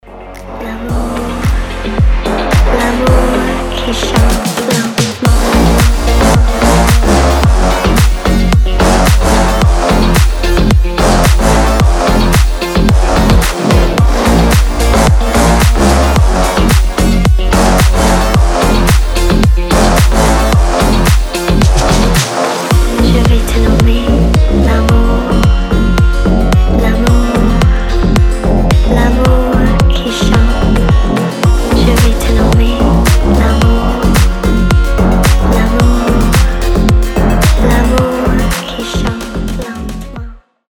• Качество: 320, Stereo
гитара
громкие
deep house
восточные мотивы
мощные басы